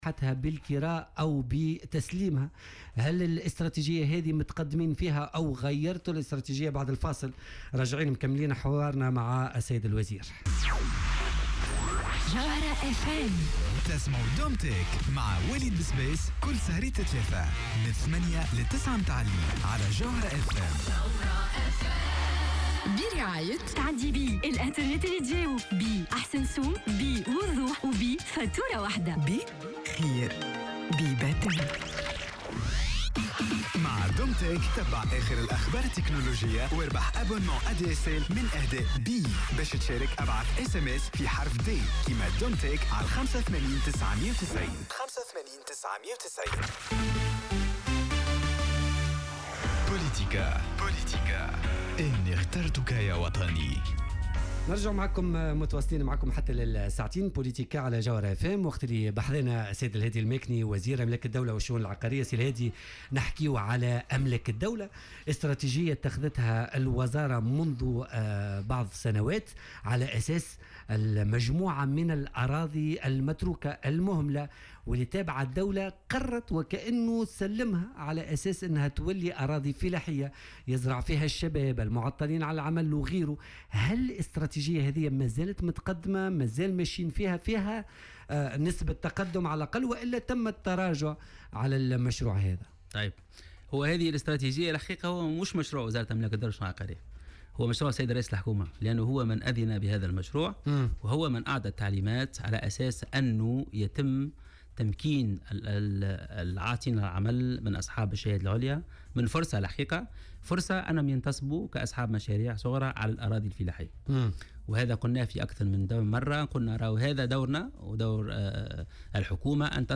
أكد وزير أملاك الدولة والشؤون العقارية الهادي الماكني ضيف بوليتيكا اليوم...